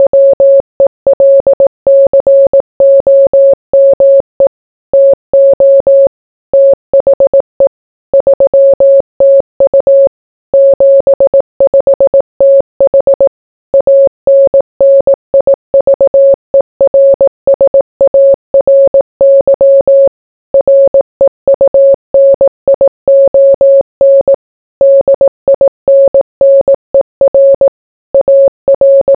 The Morse Welcome to the 3TU Reunion